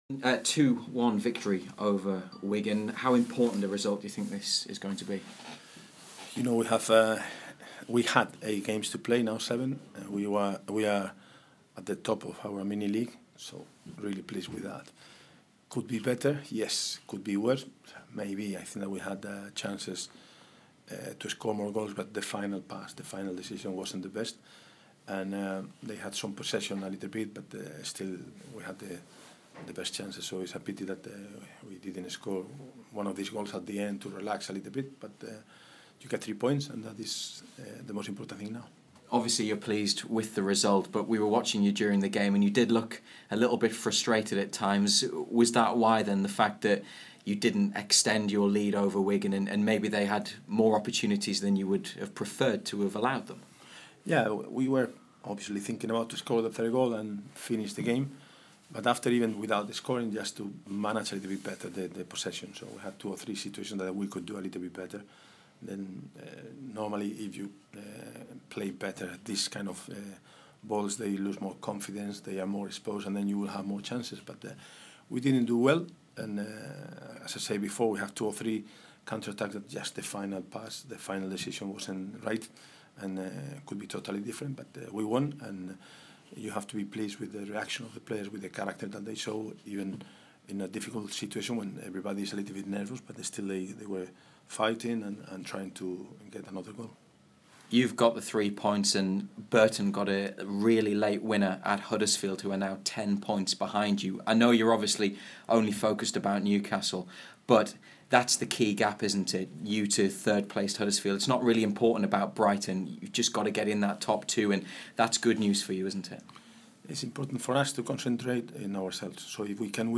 Rafa Benítez spoke to BBC Newcastle after his side defeated Wigan Athletic 2-1 at St. James' Park.